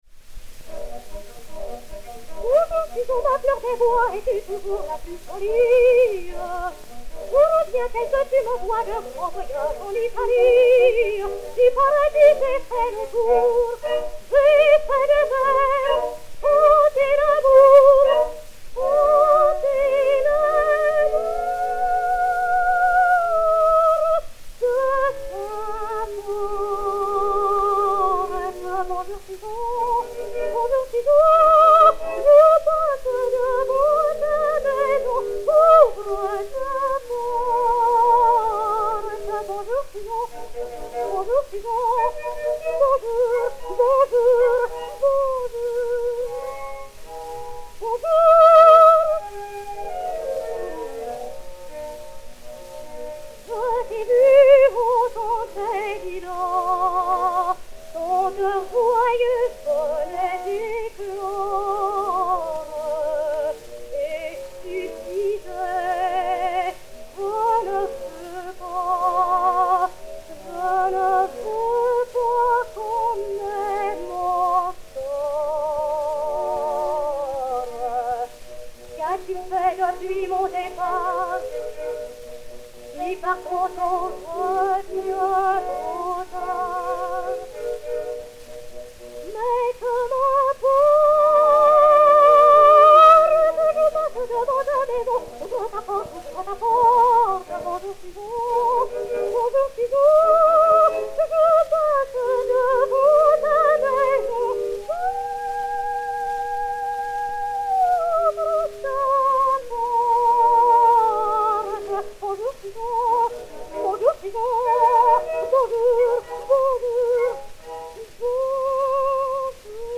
Jeanne Marié de l'Isle (Carmen) et Orchestre
Zonophone X 83052, mat. 5146o, enr. à Paris en 1905